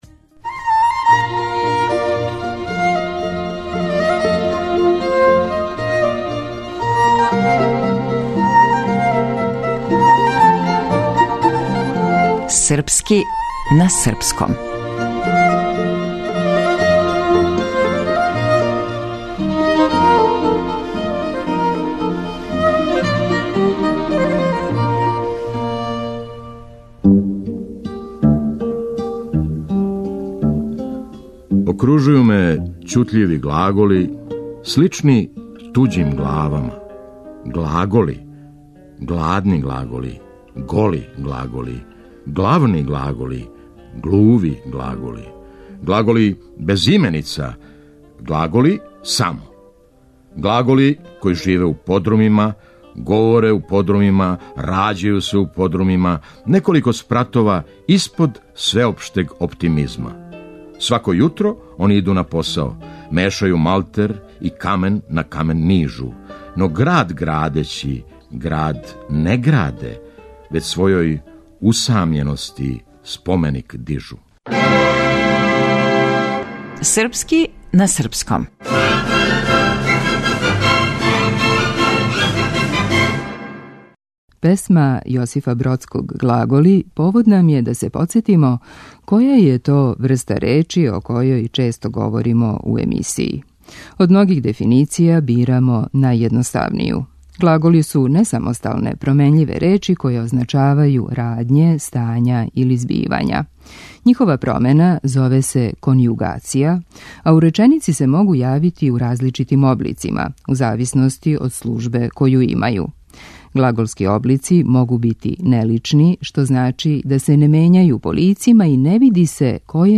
Драмски уметник - Феђа Стојановић